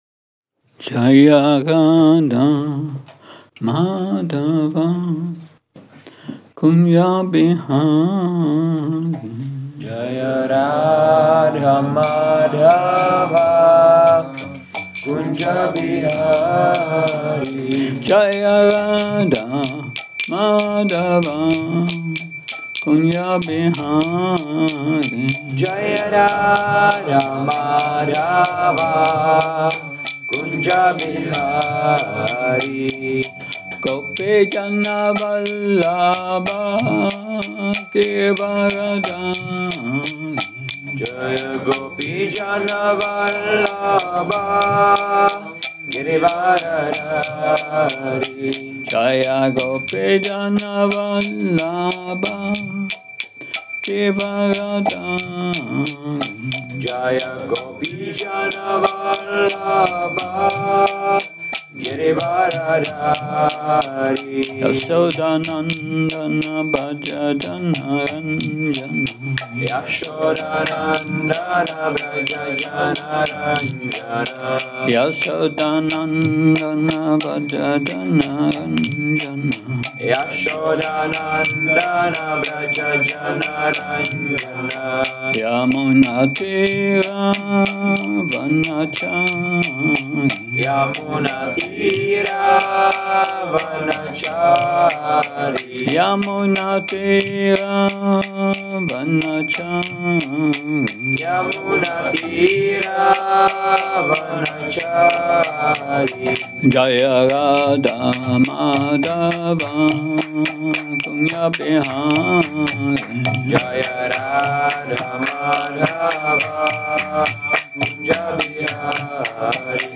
Přednáška SB-11.13.19 – Šrí Šrí Nitái Navadvípačandra mandir